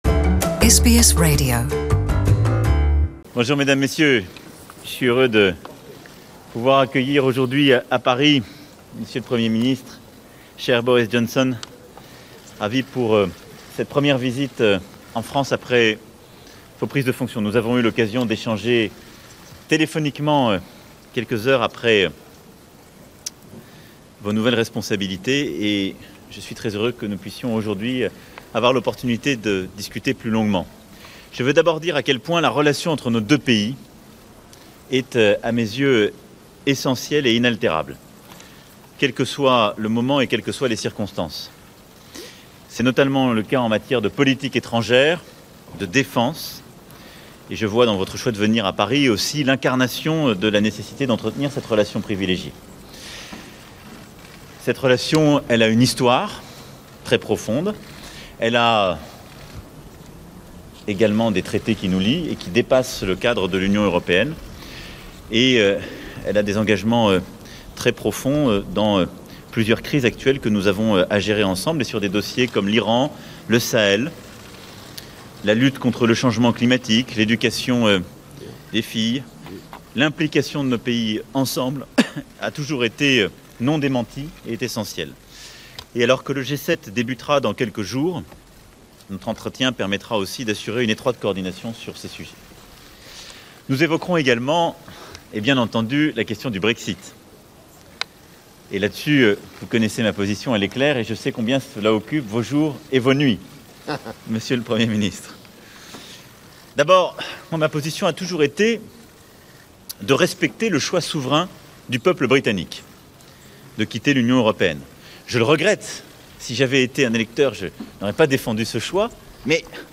French President Emmanuel Macron and British Prime Minister Boris Johnson live a press conference prior to their meeting at the Elysee Palace in Paris Source: AAP